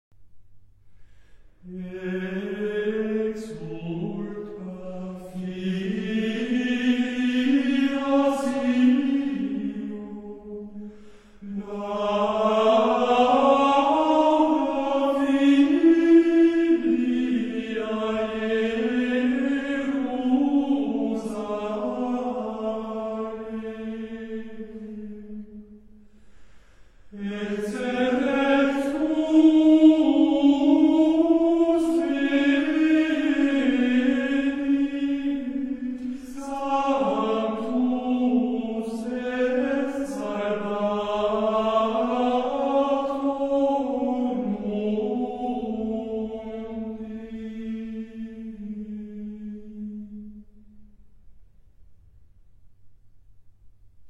Песнопения Рождества в исполнении школы хорала при Венской придворной капелле Хофбурга (Австрия).